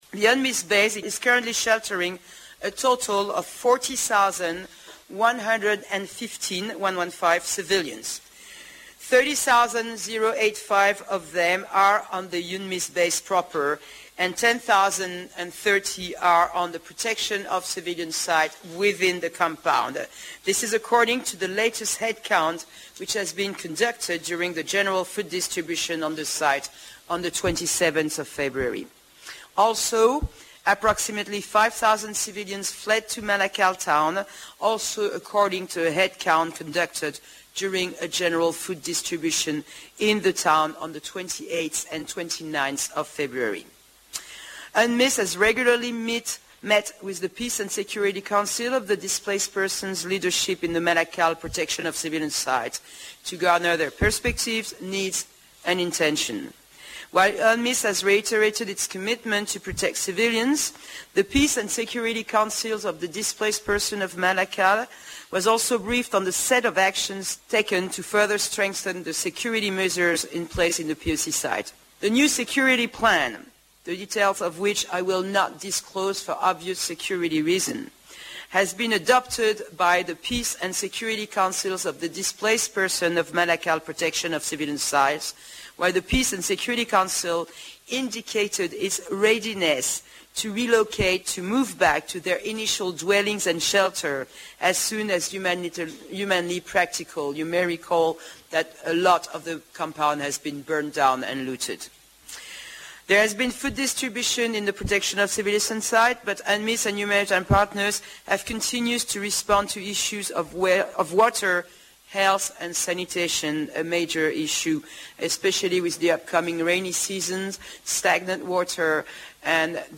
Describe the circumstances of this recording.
She was speaking at the weekly UNMISS press briefing.